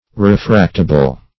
Refractable \Re*fract"a*ble\ (-?*b'l), a.